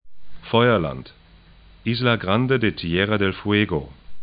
'fɔyɐlant
'i:zla 'grandə de ti'ɛra dɛl 'fŭe:go